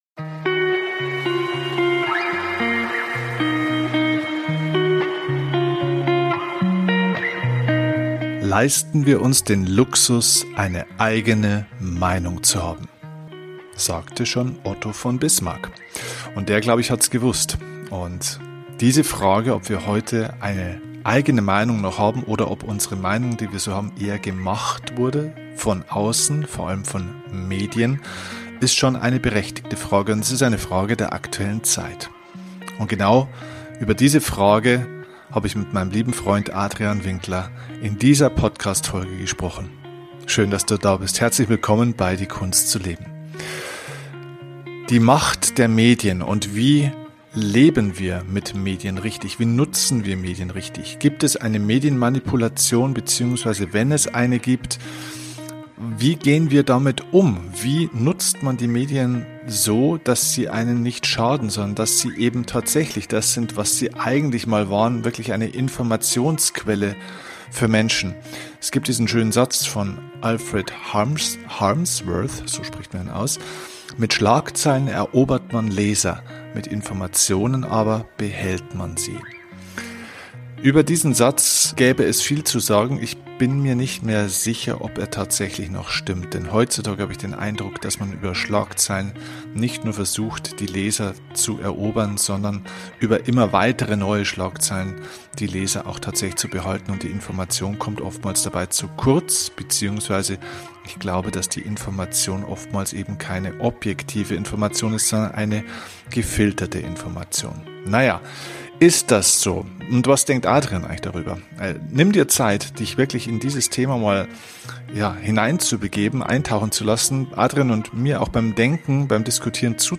Wie immer bei diesem Format: kein Skript, nur zwei Freunde die miteinander reden.